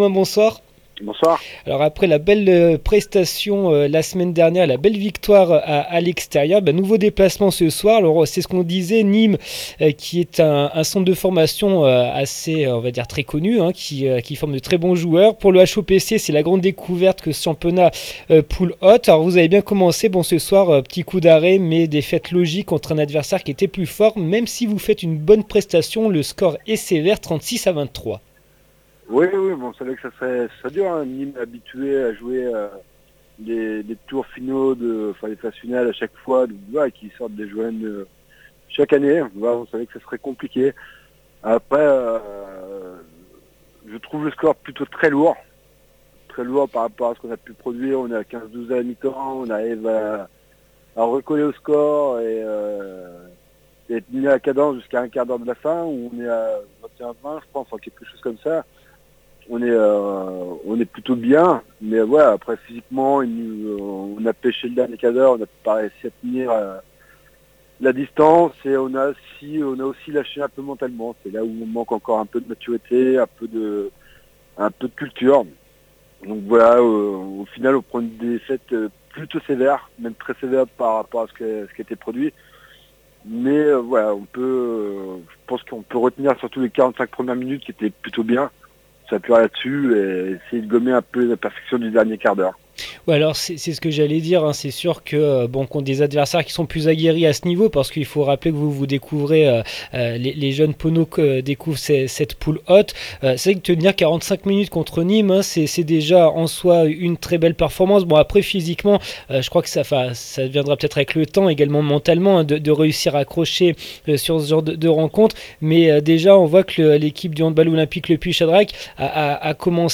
MATCH HAND BALL U18 M NATIONALE 2EME PHASE NIMES 36 HOPC LE PUY 23 REACTIONS APRES MATCH